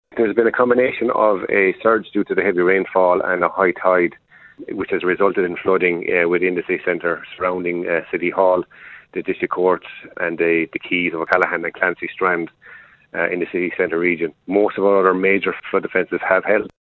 Limerick councillor Daniel Butler has given an update on the areas which have been worst affected: